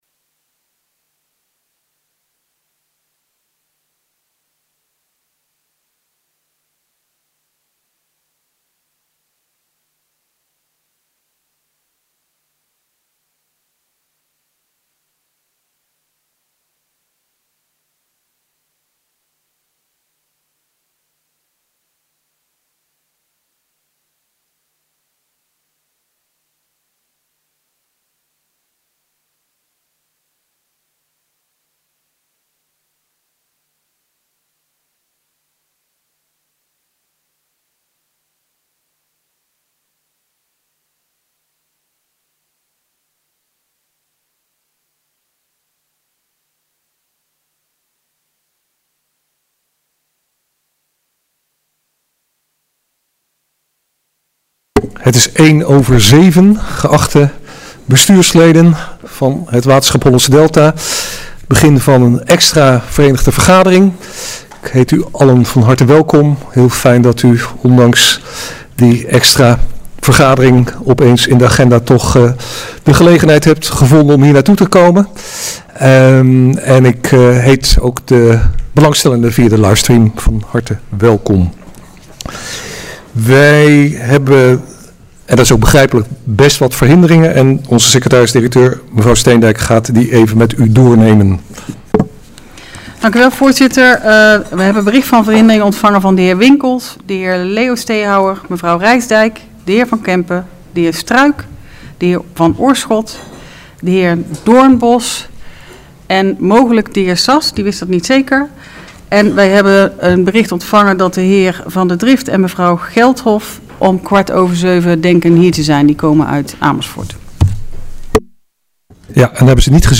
Agenda WSHD - Besluitvormende VV donderdag 6 oktober 2022 19:00 - 20:30 - iBabs Publieksportaal
Het Schap, Handelsweg 110 te Ridderkerk